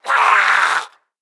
DayZ-Epoch/dayz_sfx/zombie/chase_1.ogg at 56c310400f72e7c09bca96be95ecbc6f9dbbeee4